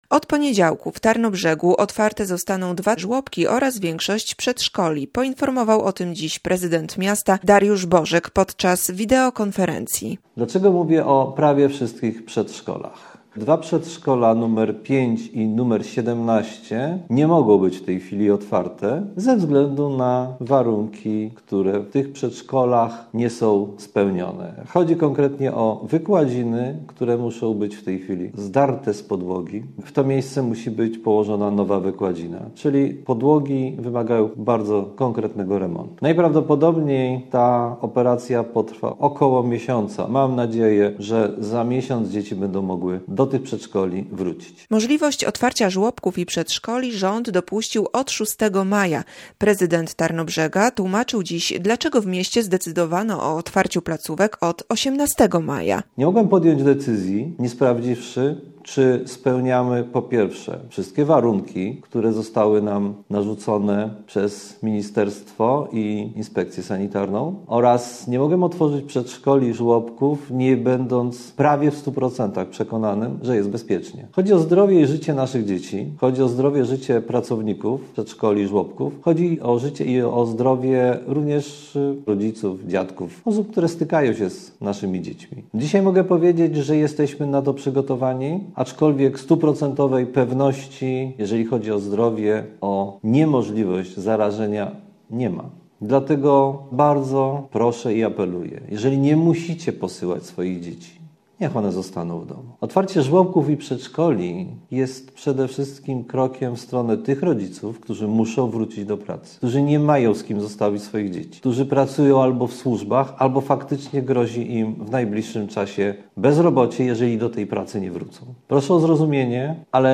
Mówił o tym podczas wideokonferencji prezydent miasta, Dariusz Bożek.